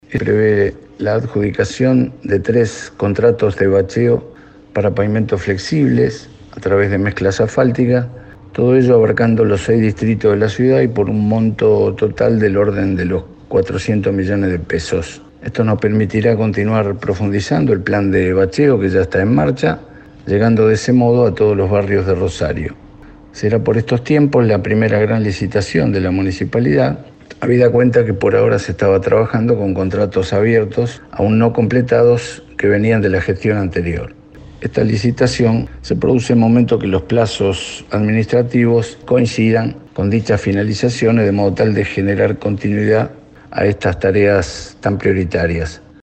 JOSÉ-LUIS-CONDE-Sec.-de-Obras-Públicas-de-la-Municipalidad-de-Rosario.mp3